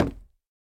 Minecraft Version Minecraft Version 1.21.5 Latest Release | Latest Snapshot 1.21.5 / assets / minecraft / sounds / block / chiseled_bookshelf / break1.ogg Compare With Compare With Latest Release | Latest Snapshot
break1.ogg